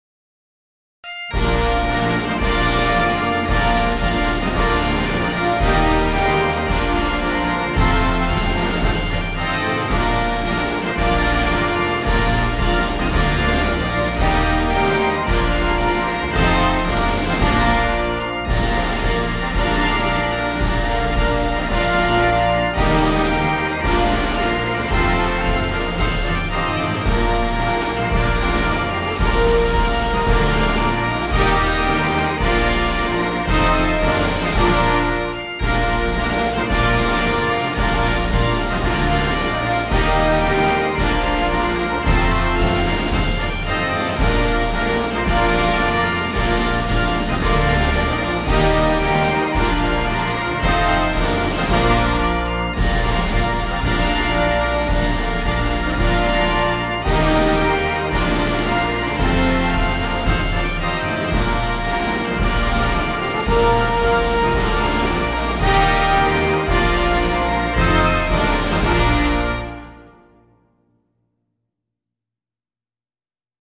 KeyBb Major
Piccolo
Timpani
Side Drum
Cymbals / Bass Drum